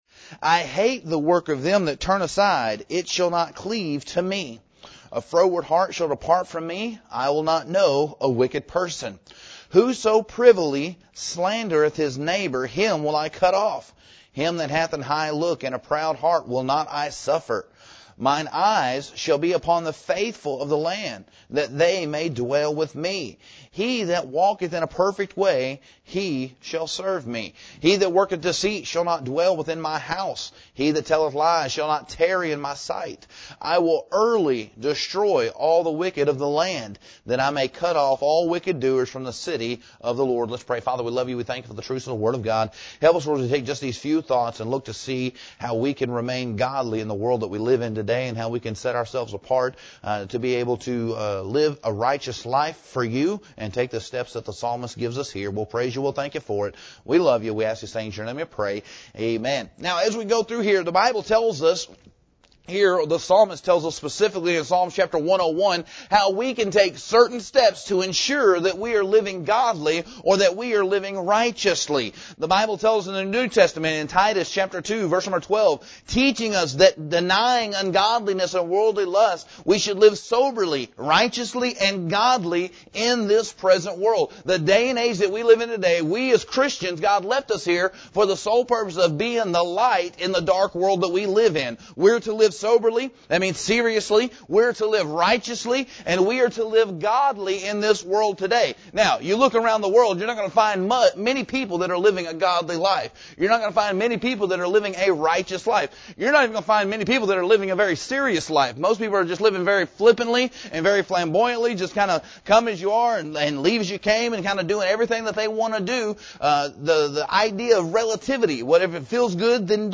preaches from Psalm 101